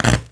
spark.wav